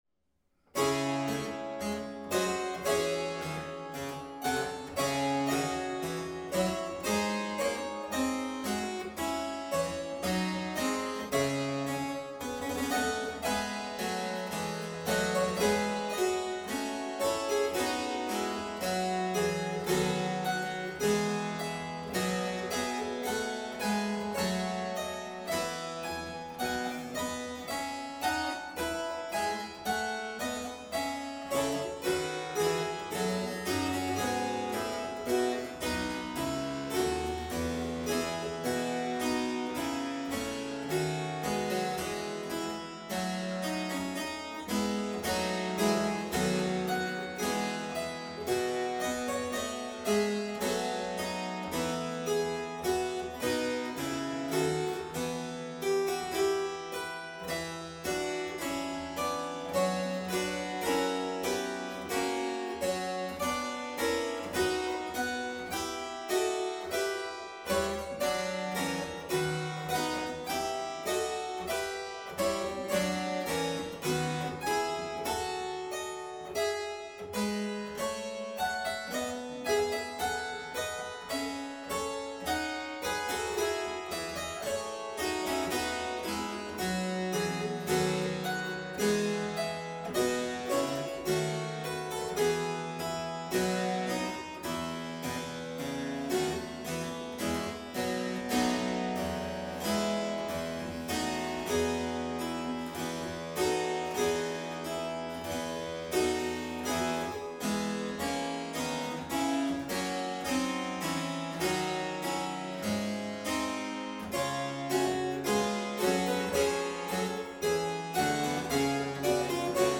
harpsichordist and conductor
The operative word is moderato , tempered by andante .
These tempi are slightly on the slow side of an Allegro moderato .
Scarlatti _Sonata_D_minor_K52.mp3